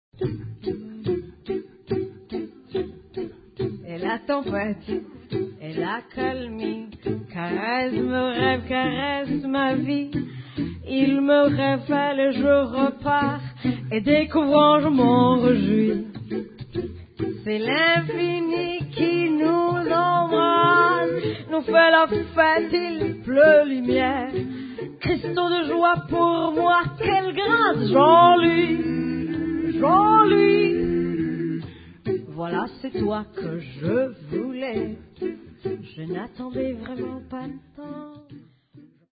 Violin
Sax, vocals
Grand Piano
Guitars
Live recording Nieuwe de la Mar theater Amsterdam